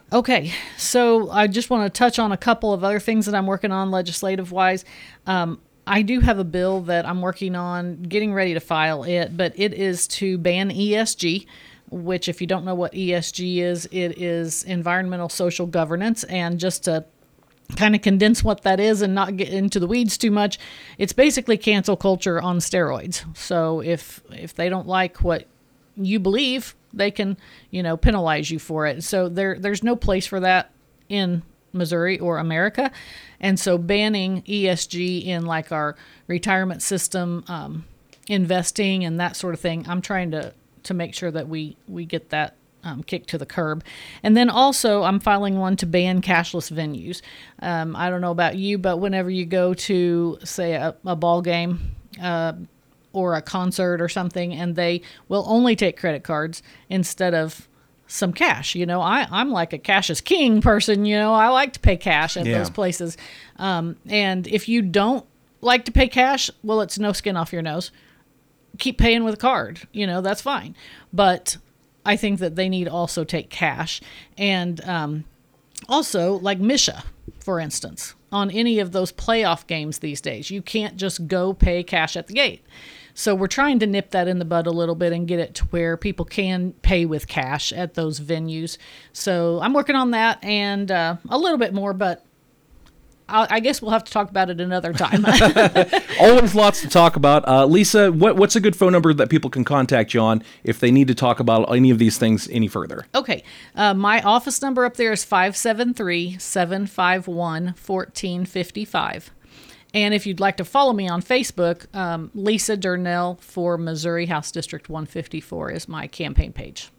West Plains, MO. – Last Friday, Lisa Durnell, Missouri Representative of the 154th District, stepped into the studio to discuss her year in-office during 2025, and what she expects our of 2026.